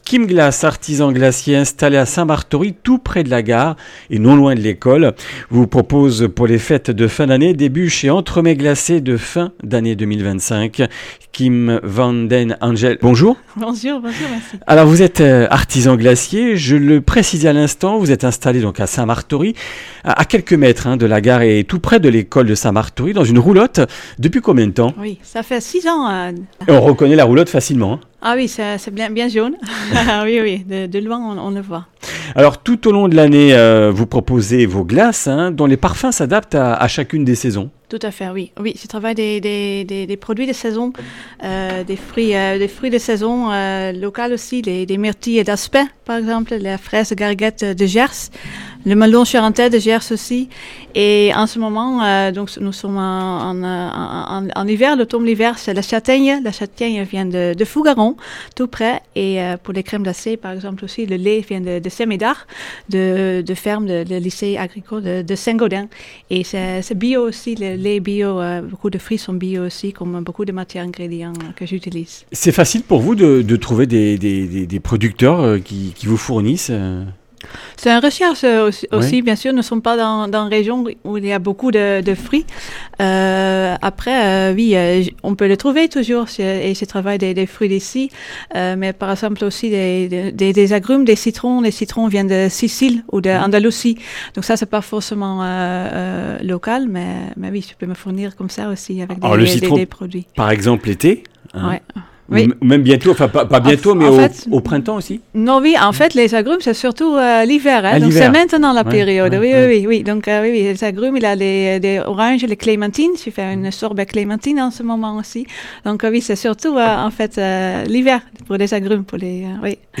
Comminges Interviews du 01 déc.